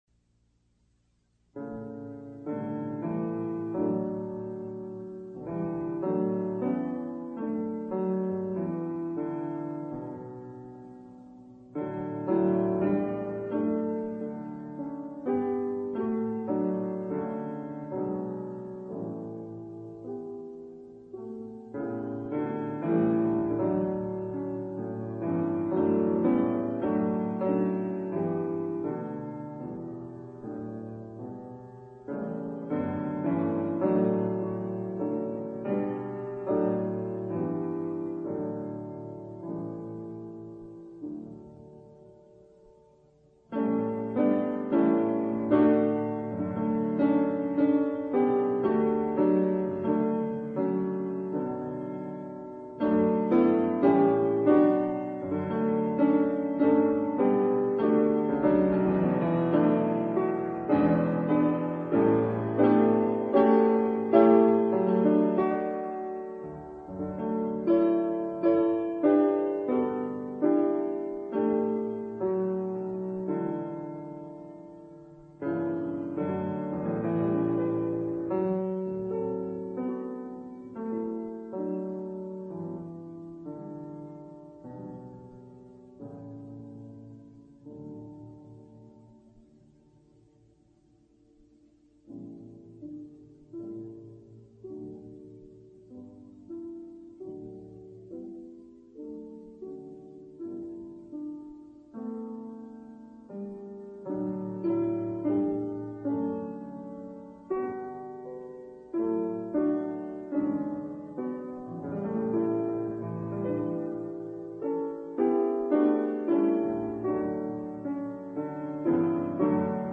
Robert Helps, Auditorium > Robert Helps plays P. Grainger More about Percy Grainger Two transcriptions for solo piano (live, unreleased performances) Irish Tune from County Derry Fauré’s Nell , op. 18 no. 1